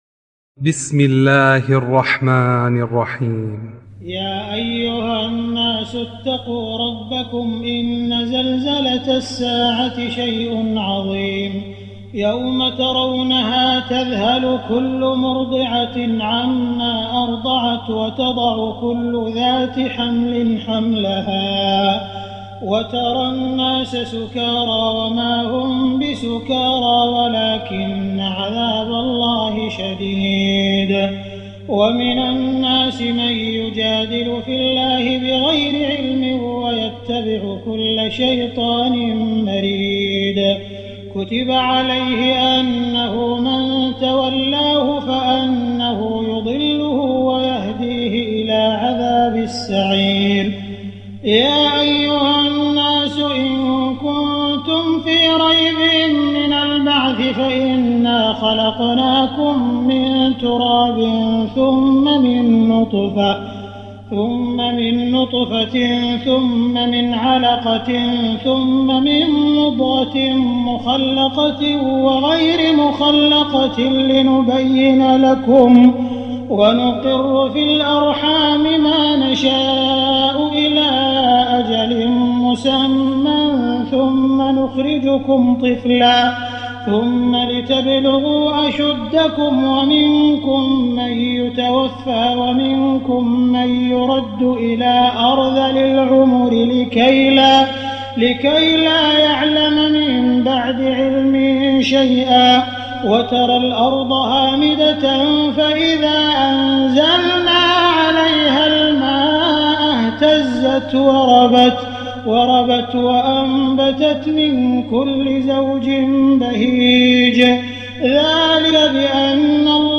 تراويح الليلة السادسة عشر رمضان 1419هـ سورة الحج كاملة Taraweeh 16 st night Ramadan 1419H from Surah Al-Hajj > تراويح الحرم المكي عام 1419 🕋 > التراويح - تلاوات الحرمين